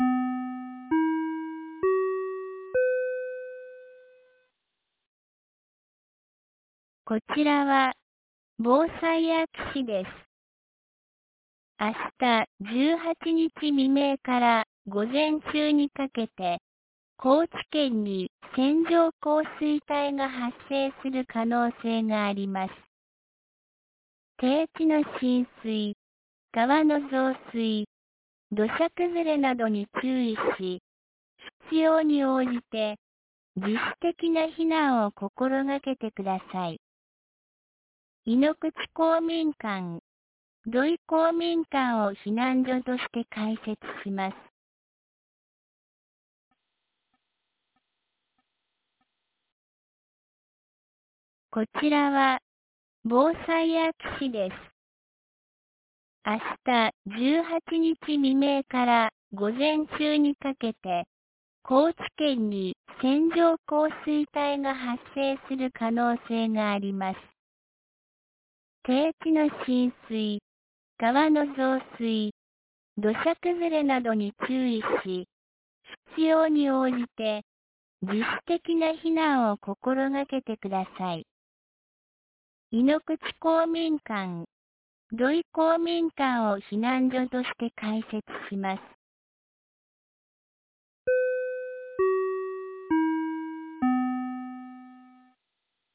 2024年06月17日 16時50分に、安芸市より井ノ口、土居、僧津へ放送がありました。